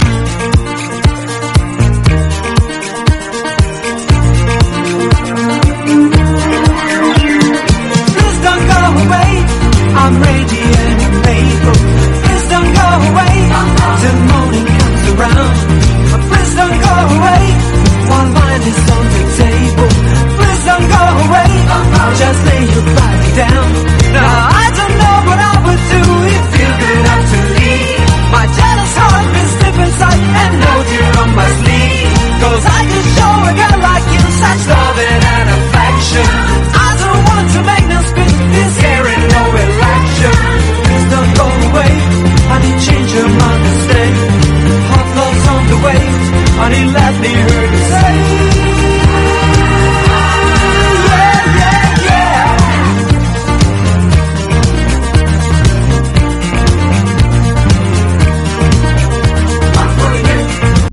NEW SOUL / FREE SOUL
美しいストリングスの響きが今聴いても素晴らしすぎるエヴァーグリーンな名作